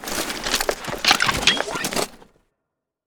combat / weapons / rocket / draw.wav
draw.wav